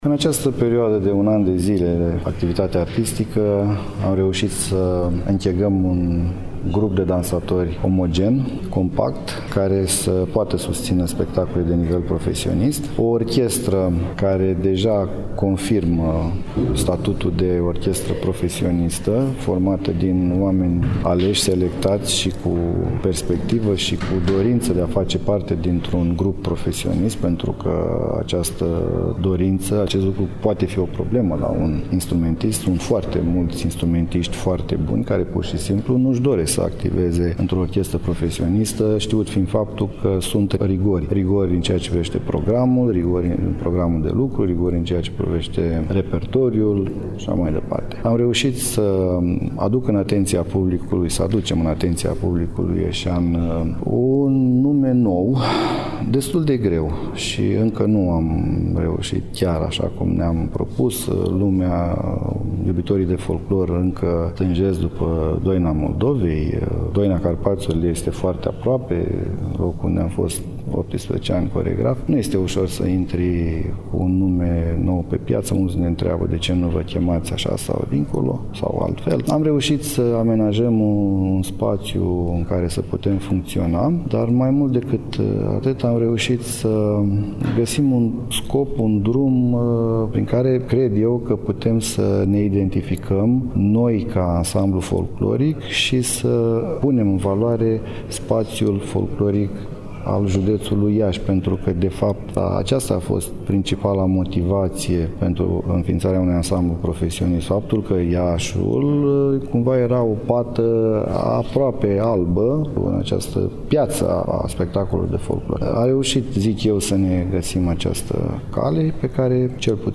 Despre activitatea și planurile ansamblului s-a făcut vorbire în conferința de presă (marți, 26 noiembrie 2019 ora 12:00), la sediul Ansamblului Artistic Profesionist ,,Constantin Arvinte” al Consiliului Județean Iași.